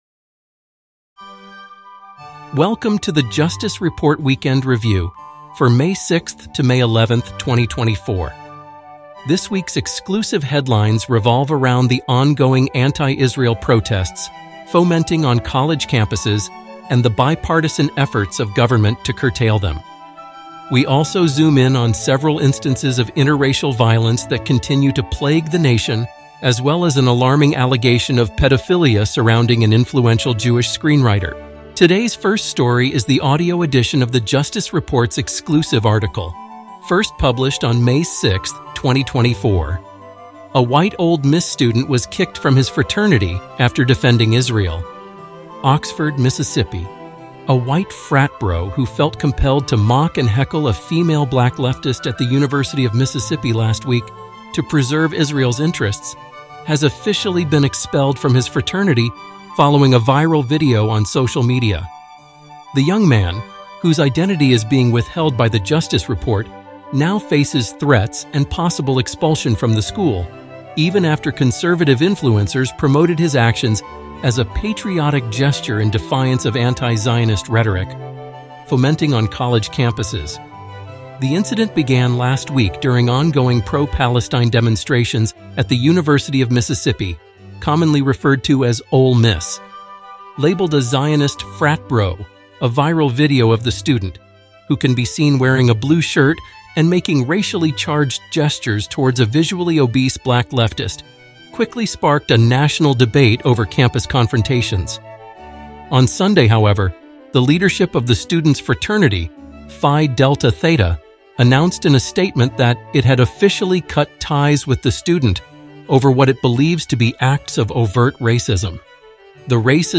The first episode of the Justice Report Weekend Review covers May 6th to 11th, 2024. This podcast is intended to be a longer-form, easy-listening, NPR-inspired show available to our paid subscribers.